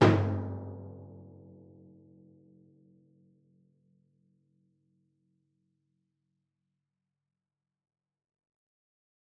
Timpani Large
Timpani7C_hit_v5_rr2_main.mp3